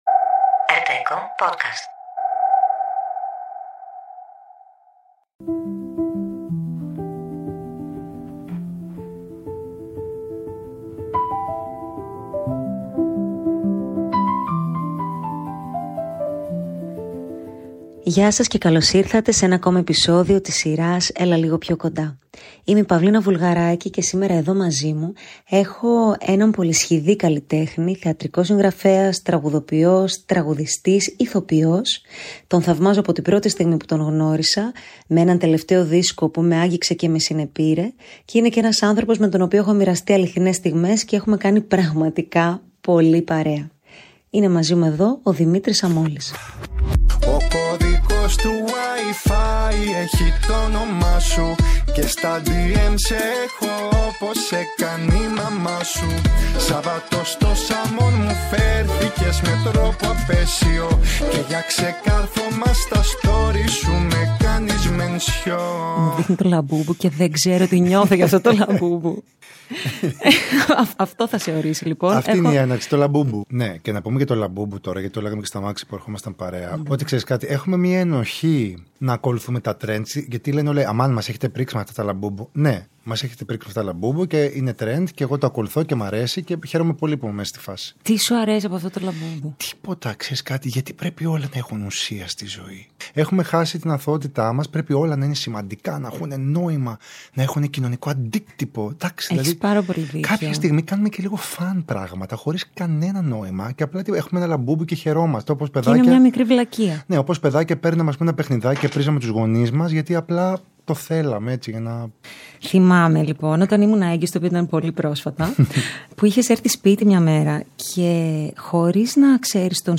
Μια βαθιά και αληθινή συζήτηση